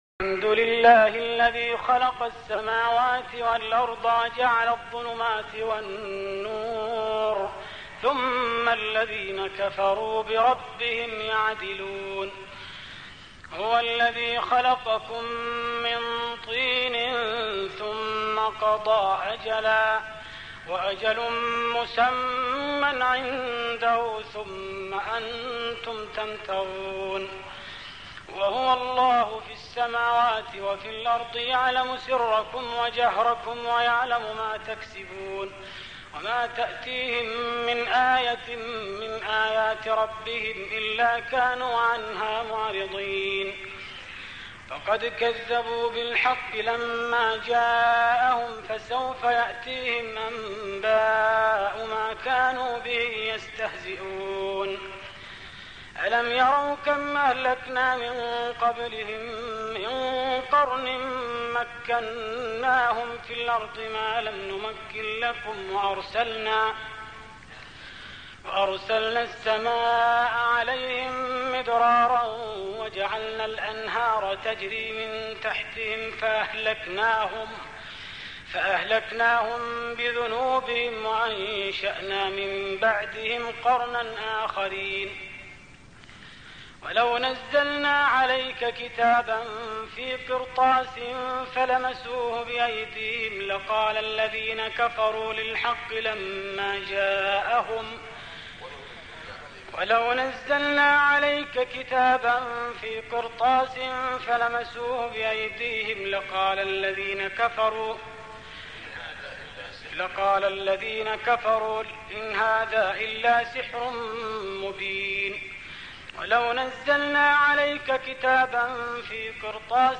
تهجد رمضان 1417هـ من سورة الأنعام (1-43) Tahajjud Ramadan 1417H from Surah Al-An’aam > تراويح الحرم النبوي عام 1417 🕌 > التراويح - تلاوات الحرمين